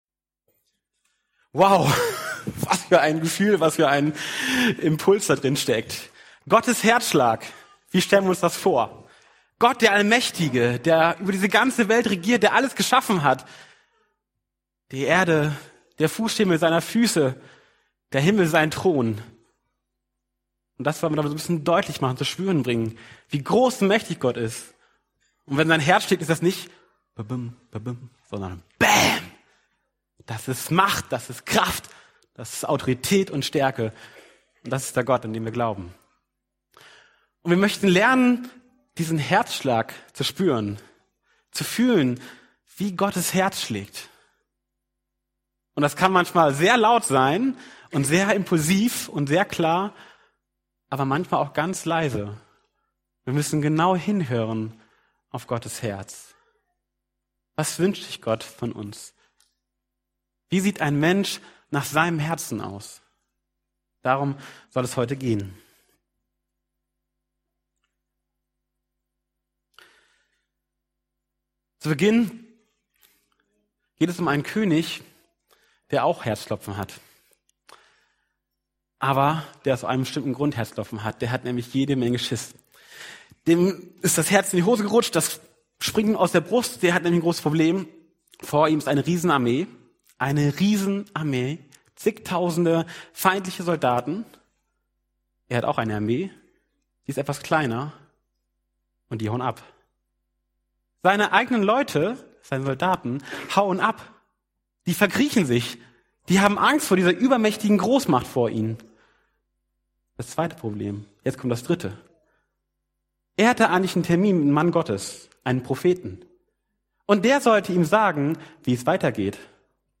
– FeG Oldenburg